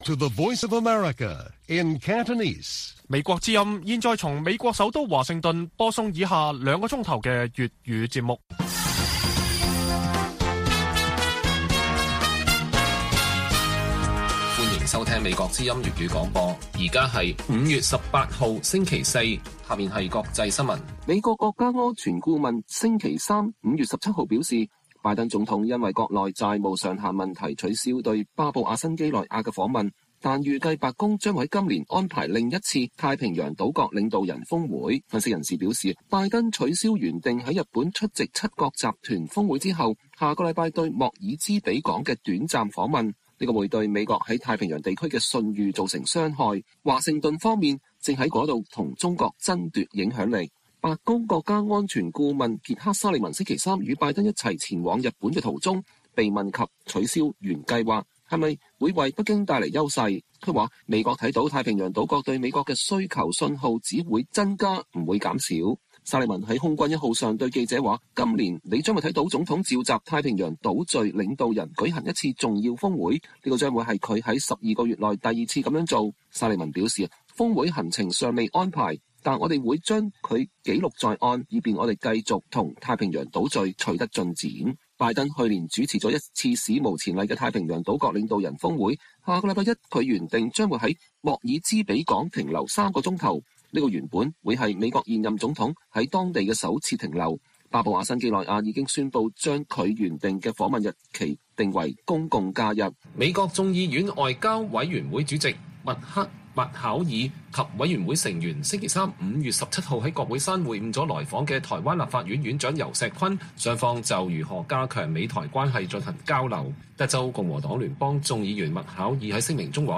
粵語新聞 晚上9-10點: 拜登計劃再召集太平洋島國峰會彌補缺席巴新峰會